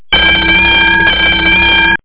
CLOCK.mp3